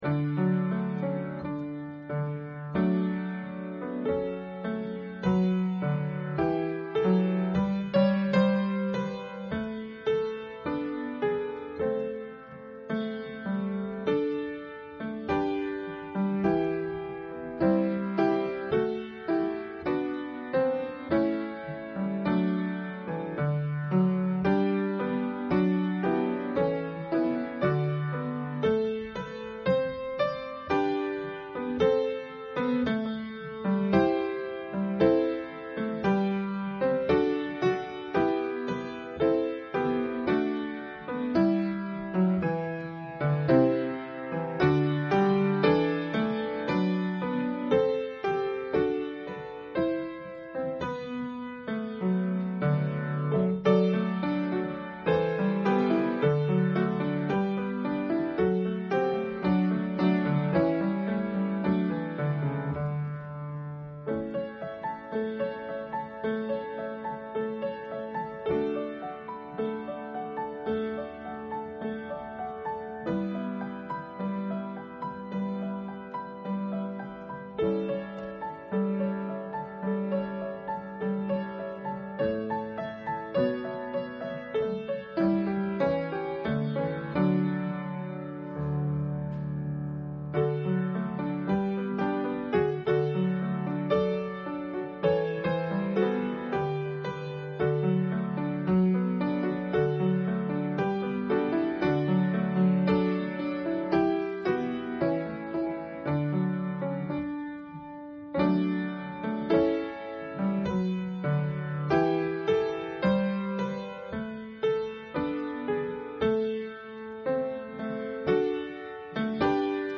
Public Reading of Holy Scripture